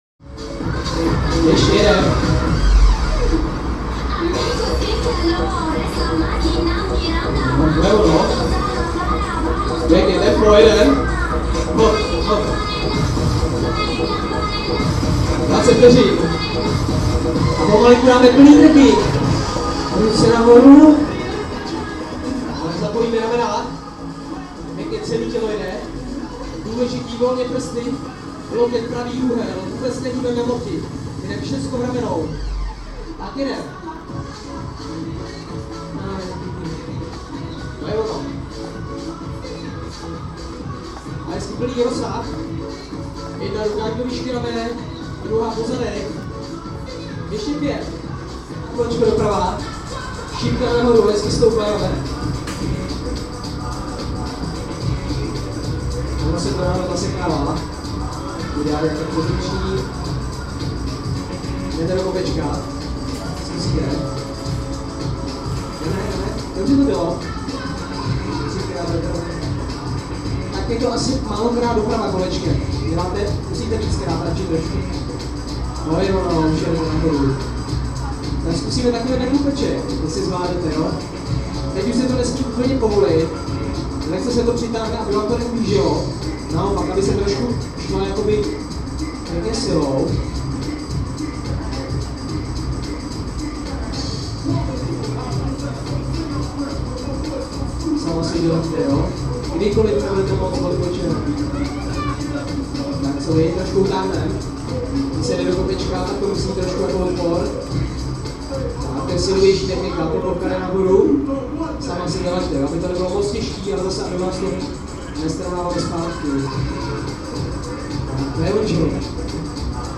Tagy: exteriér hudba interiéry lidé sport
Zvuk z okna tělocvičny, vedle stanice metra Invalidovna na pomezí Karlína a Libně.